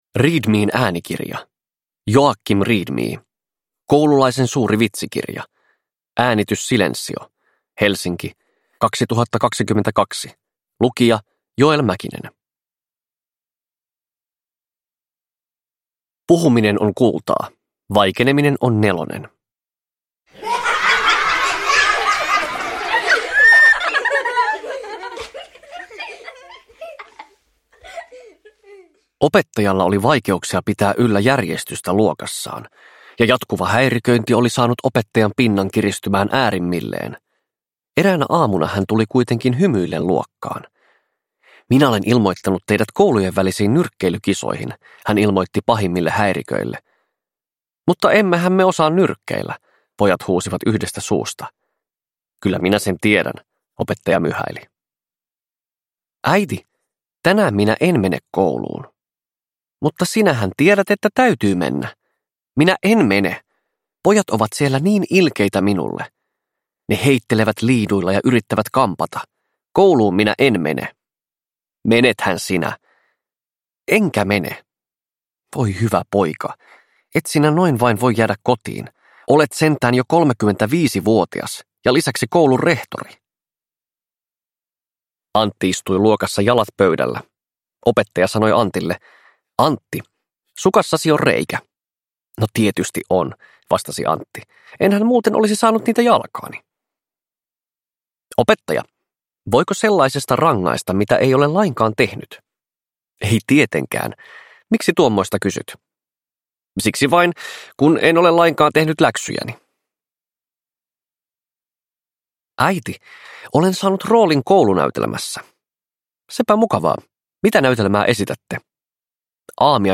Koululaisen suuri vitsikirja – Ljudbok